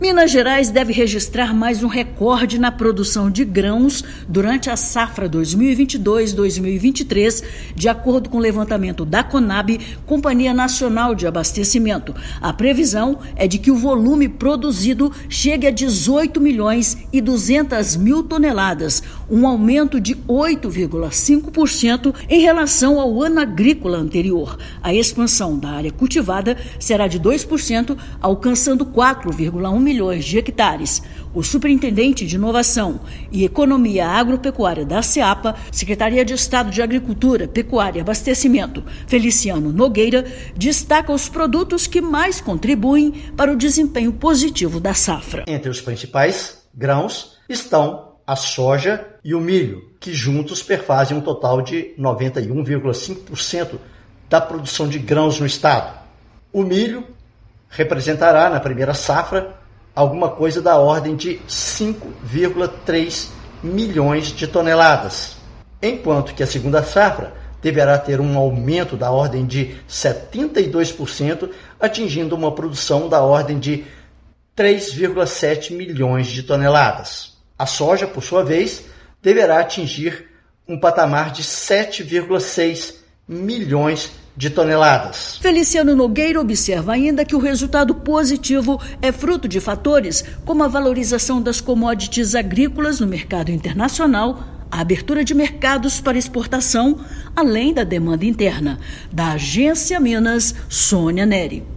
Minas Gerais deve registrar mais um recorde na produção de grãos durante a safra 2022/2023, conforme o primeiro levantamento da Companhia Nacional de Abastecimento (Conab).Ouça matéria de rádio.